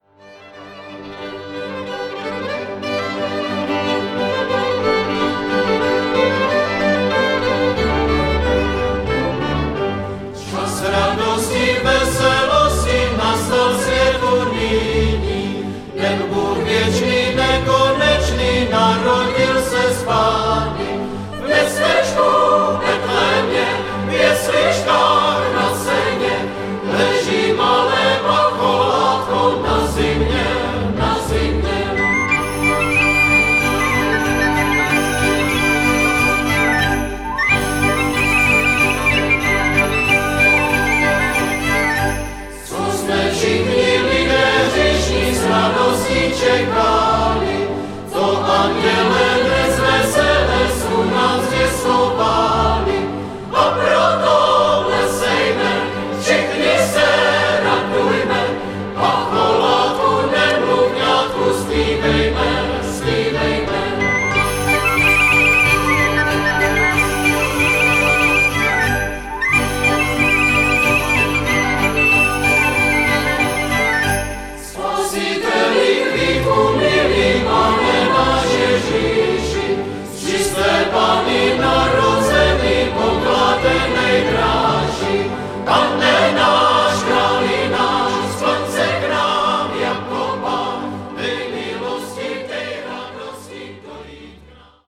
lidová
zpěv